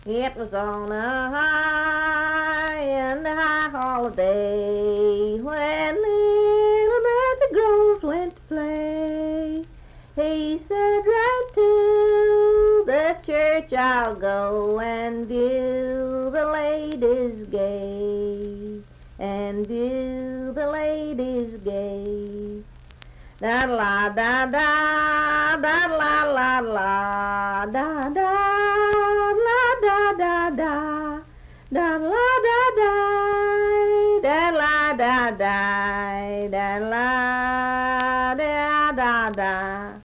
Unaccompanied vocal music
Verse-refrain 2(4).
Voice (sung)